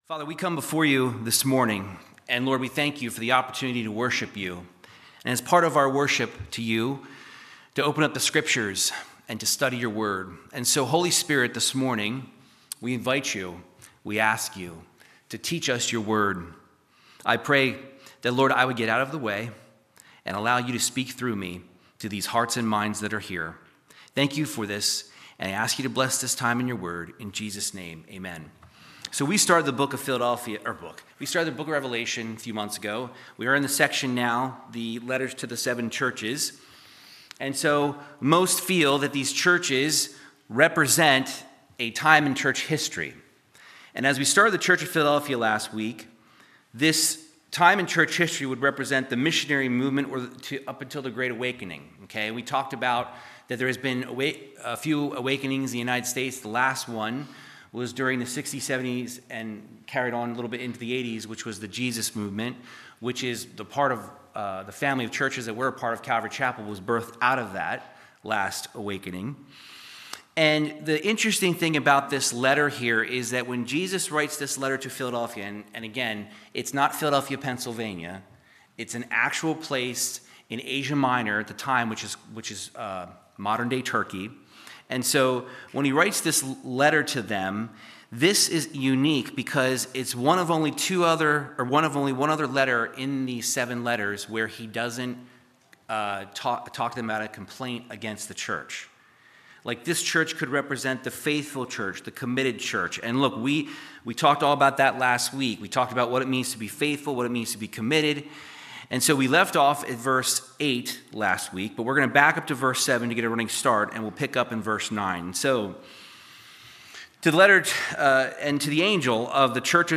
Verse by verse Bible teaching through the book of Revelation 3:9-13